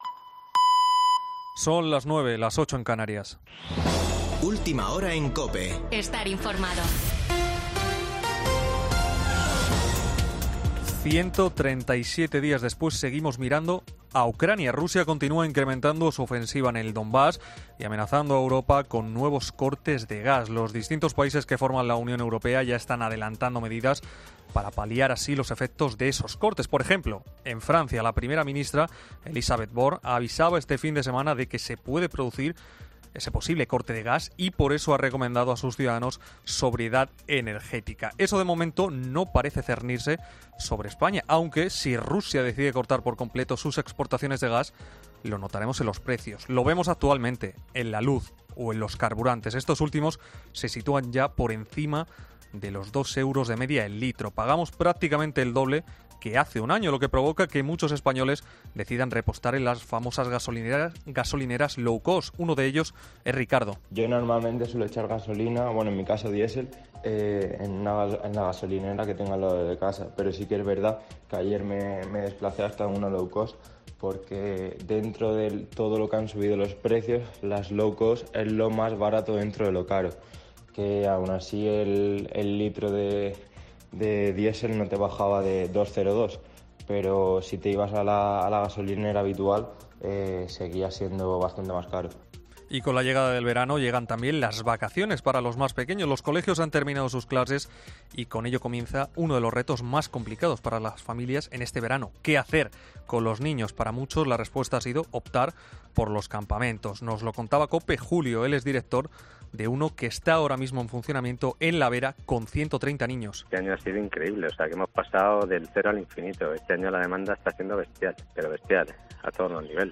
Boletín de noticias de COPE del 10 de julio de 2022 a las 21:00 horas